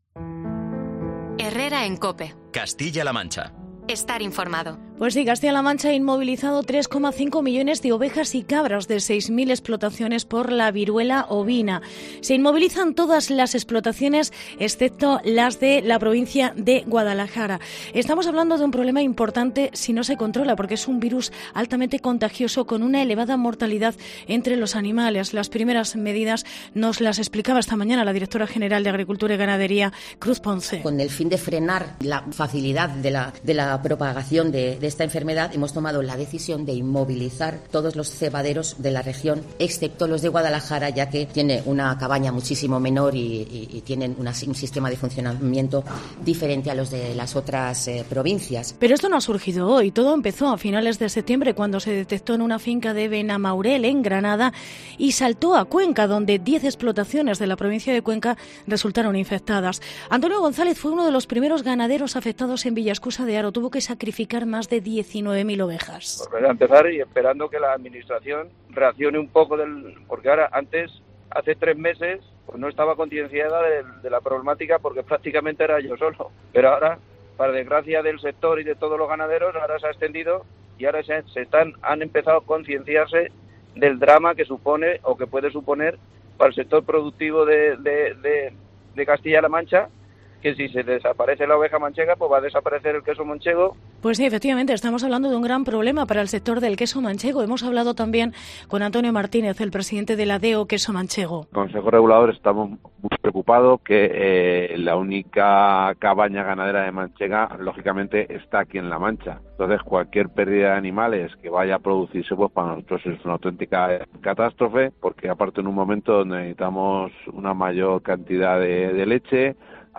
Reportaje viruela ovina en CLM